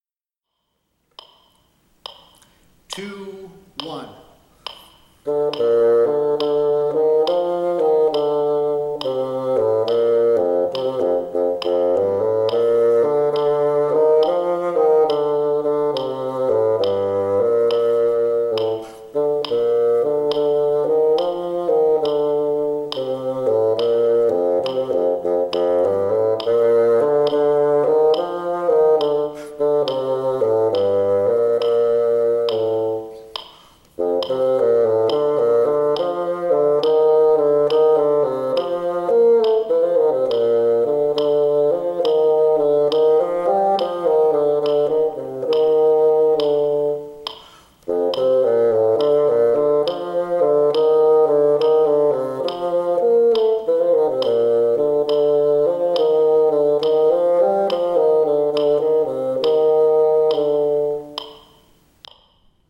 P. 24, Duet in Bb Major, Tempo 69, Bassoon 2